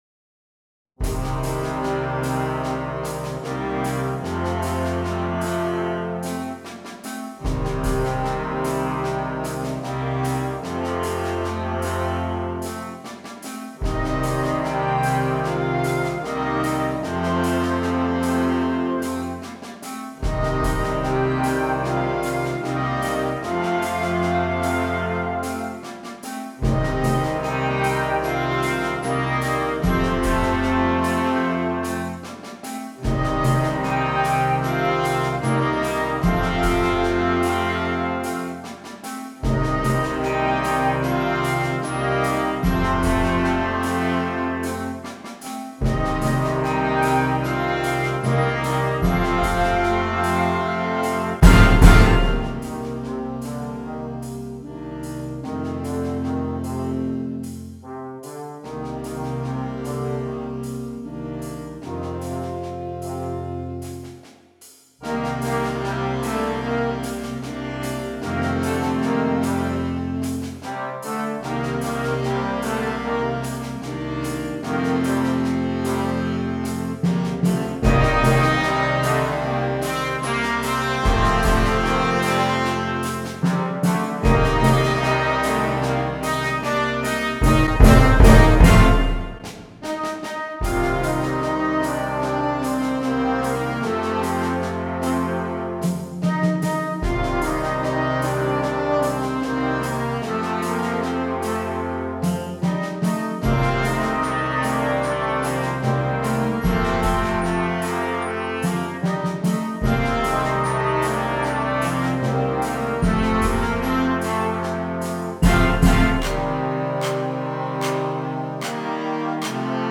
• Flauta
• Oboe
• Clarinete en Bb
• Clarinete Bajo
• Saxofón Alto
• Saxofón Tenor
• Saxofón Barítono
• Trompeta en Bb
• Corno en F
• Trombón
• Fagot
• Barítono (T.C)
• Tuba
• Timbal
• Glockenspiel
• Platillos
• Redoblante
• Bombo